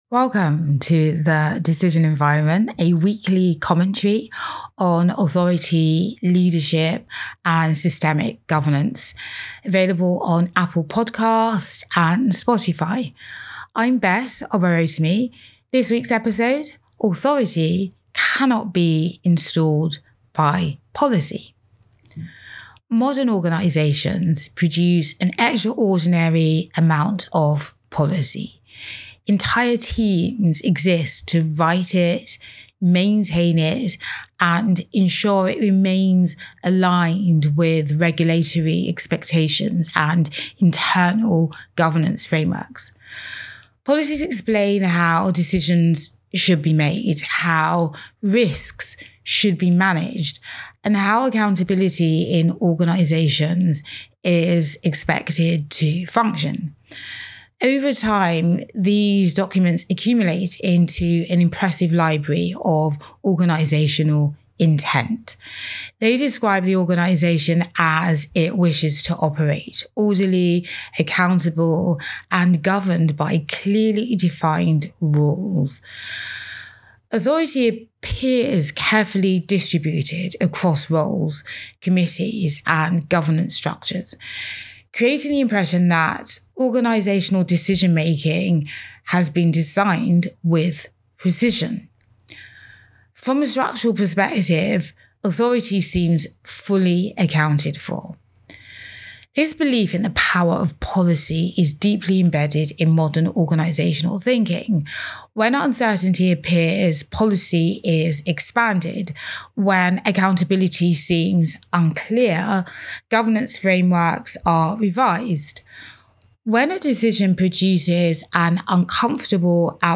Audio Commentary In this commentary I explore a simple but often overlooked reality inside organisations: decision authority cannot be installed by policy.